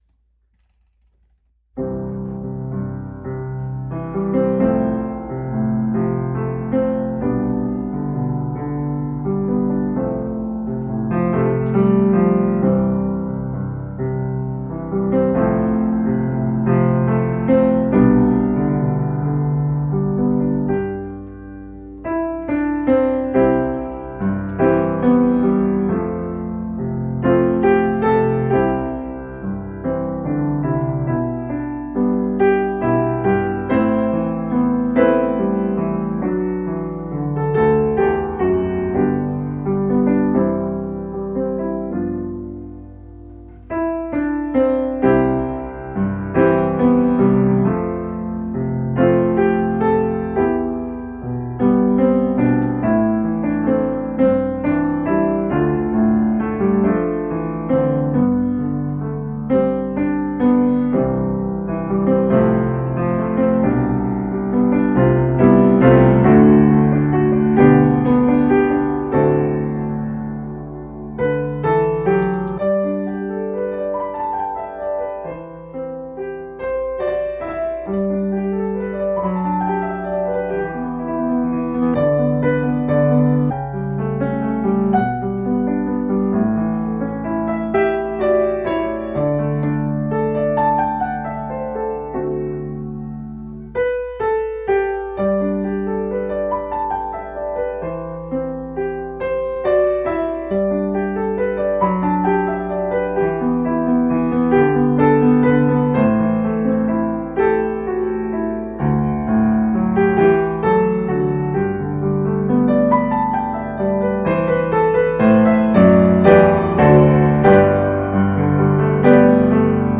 for piano solo